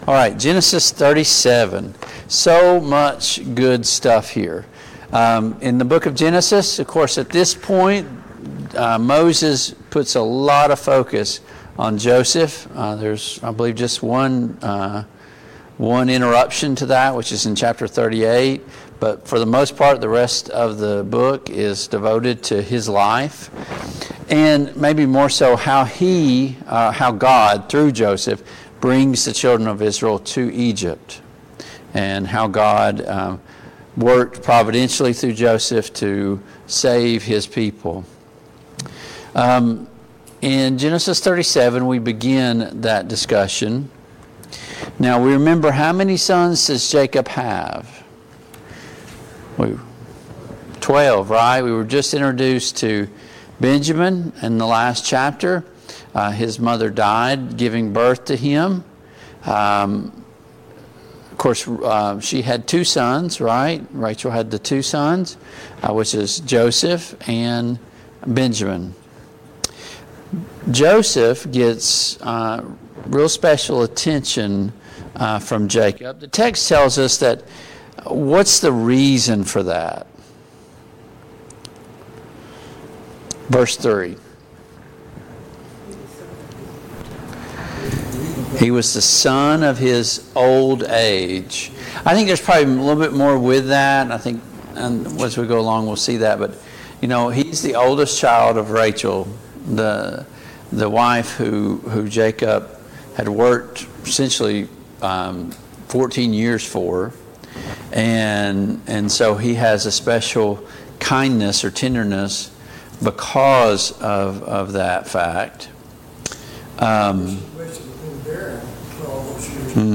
Passage: Genesis 37 Service Type: Family Bible Hour Topics